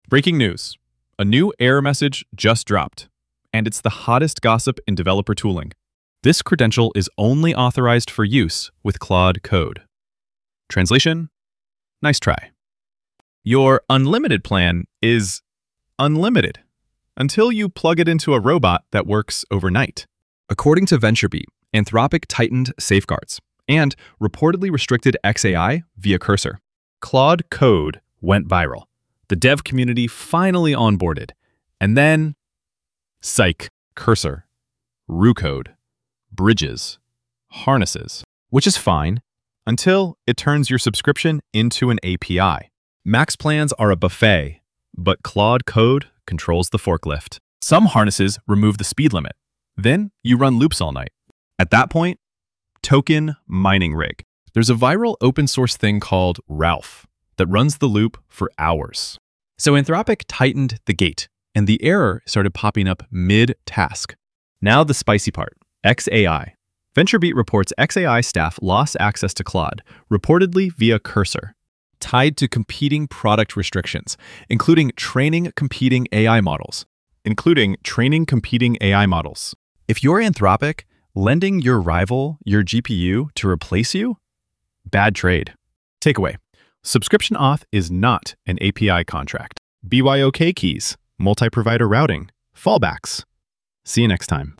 Voiceover-only